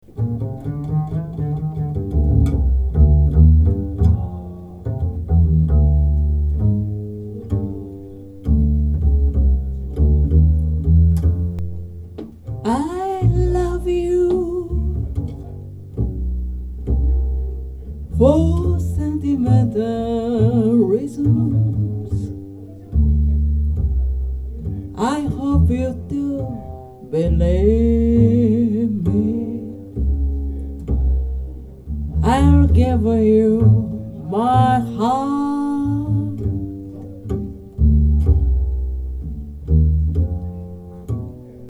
まず音のバランスが良く空間音が良く出ます。
ティンパニーの音が良く聞こえます。
良く聴くとスネアーの音が聞こえにくいです。
ノーマルの音
サンプル音源の録音状況です。
マイクはソニーＣ３７Ｐ改造